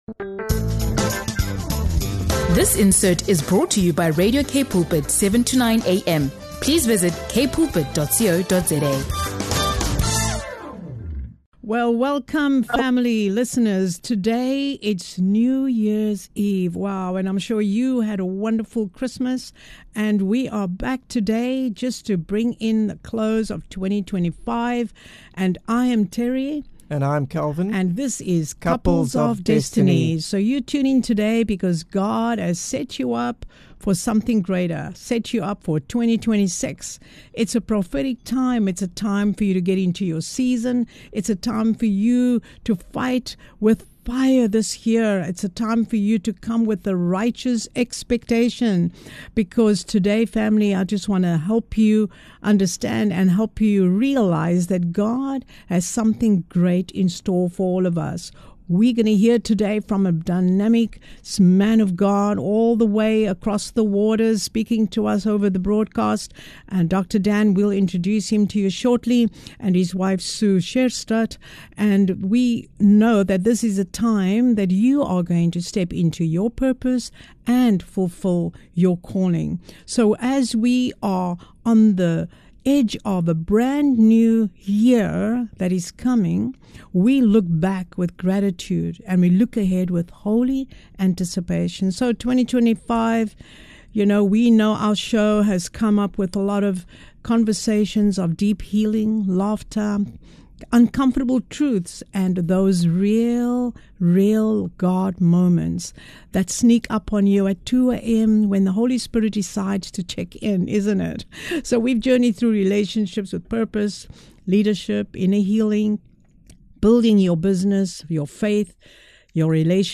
As we close the chapter on 2025 and step into 2026, Couples of Destiny hosts a powerful New Year’s Eve prophetic conversation filled with faith, healing, and divine expectation.